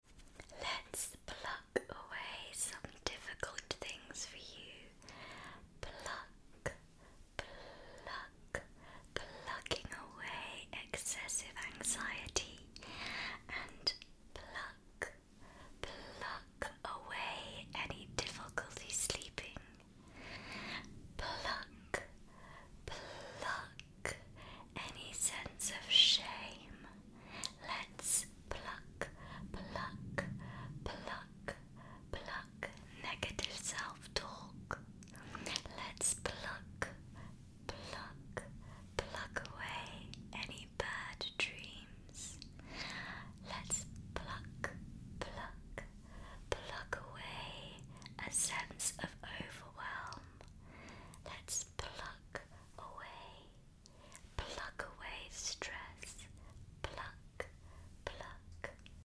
I do hope so 💚 Enjoy my British English ASMR plucking video. Personal attention ASMR to support you through difficult times.